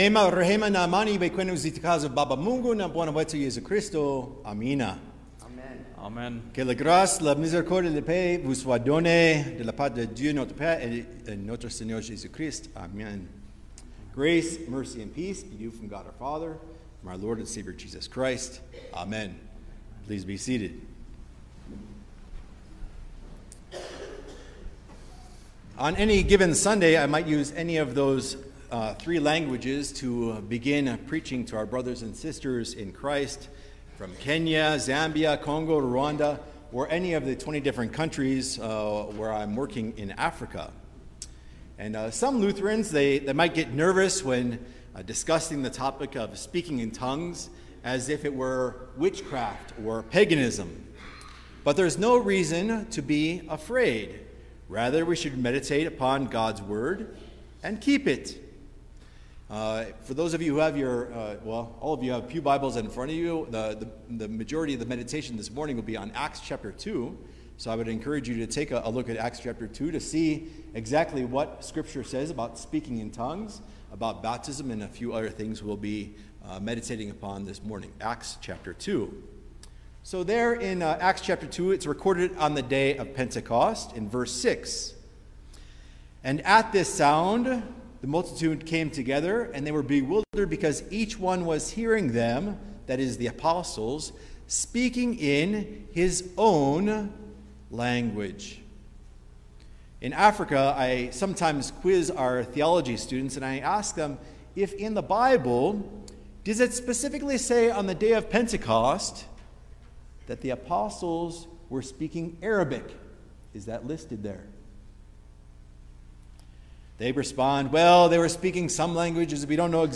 June-5_2022_The-Day-of-Pentecost_Sermon-Stereo.mp3